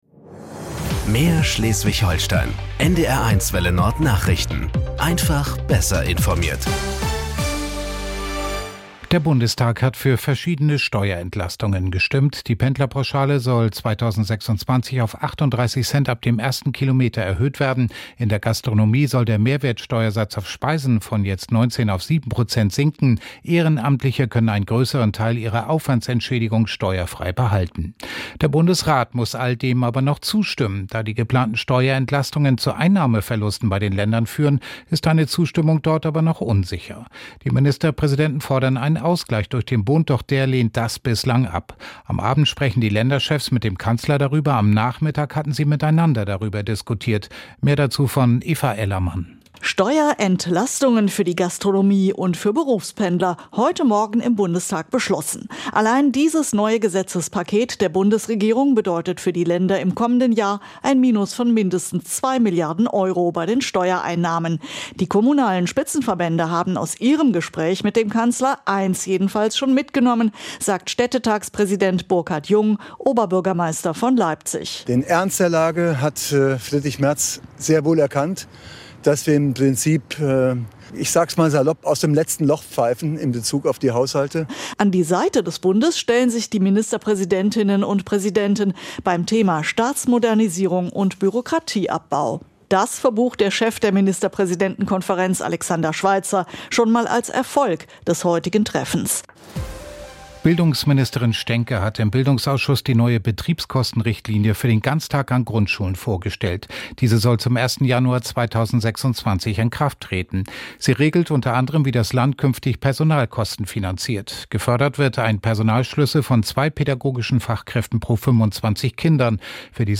Nachrichten 18:00 Uhr - 04.12.2025 ~ NDR 1 Welle Nord – Nachrichten für Schleswig-Holstein Podcast